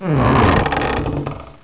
PuertaAbre.wav